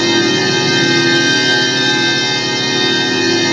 Index of /90_sSampleCDs/E-MU Producer Series Vol. 3 – Hollywood Sound Effects/Science Fiction/Brainstem